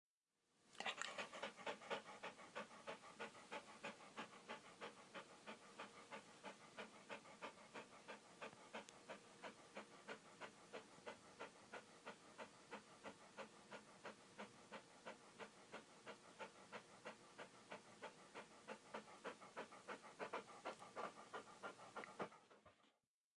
Tiếng Chó Thở hổn hển (Nhẹ nhàng hơn)
Thể loại: Tiếng vật nuôi
Description: Tiếng chó thở hổn hển nhẹ nhàng, tiếng thở dốc nhẹ, thở đều, thở nhè nhẹ, thở gấp thoáng qua, thở ngắn, thở lặng lẽ thường là âm thanh biểu hiện sự mệt mỏi, căng thẳng hoặc đơn giản là trạng thái nghỉ ngơi của chú chó...
tieng-cho-tho-hon-hen-nhe-nhang-hon-www_tiengdong_com.mp3